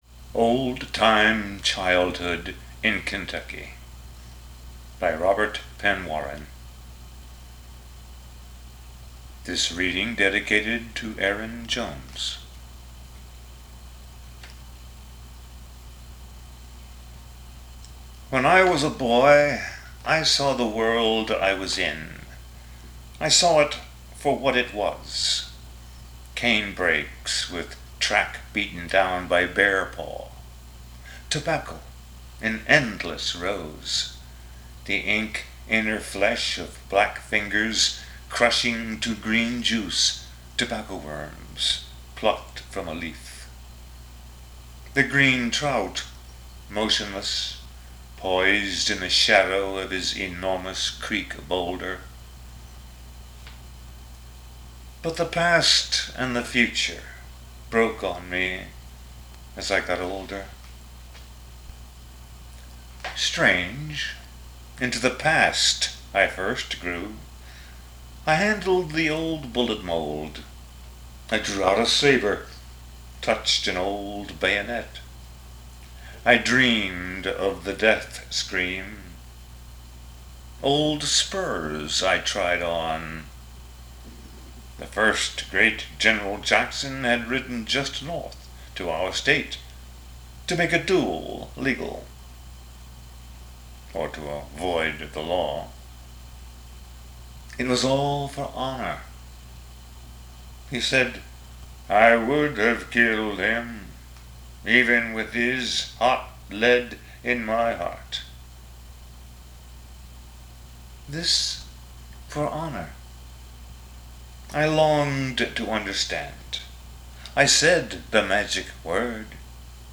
He did it himself on cassette and I just recently got it it into digital format.